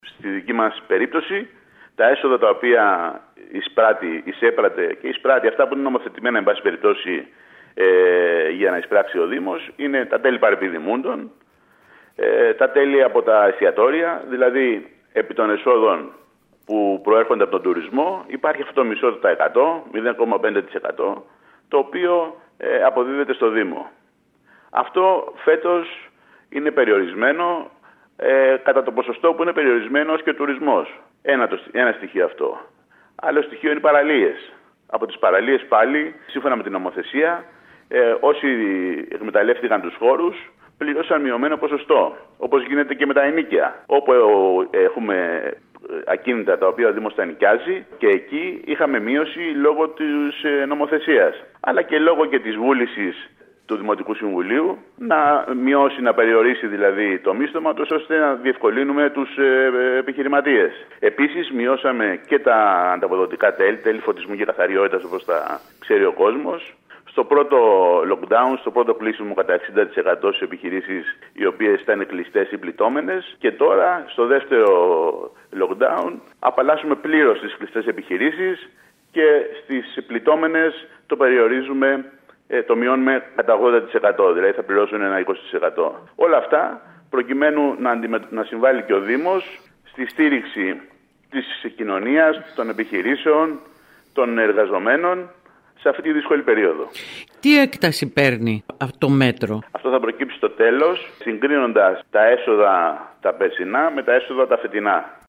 Στο τέλος Ιανουαρίου θα είναι έτοιμος ο προϋπολογισμός του 2021 του Δήμου Κεντρικής Κέρκυρας όπως δήλωσε ο αντιδήμαρχος Οικονομικών Γιώργος Παντελιός μιλώντας σήμερα στην ΕΡΑ ΚΕΡΚΥΡΑΣ. Ωστόσο από σήμερα ήδη διαφαίνεται ότι θα είναι περιορισμένα τα έσοδα του Δήμου τόσο από τα τέλη παρεπιδημούντων όσο και από τα τέλη φωτισμού και καθαριότητας.